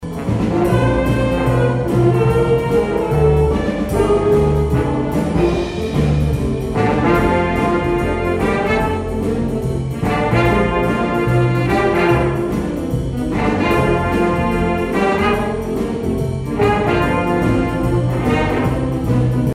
The Jazz Ensembles of Emporia State University Music Department offered a night of music to support a special cause on Thursday.
the first and second Jazz Ensembles offered a variety of songs with solos of trumpet, saxophones and trombone played by different students.
jazz-audio-1.mp3